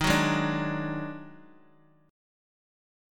EbmM7bb5 chord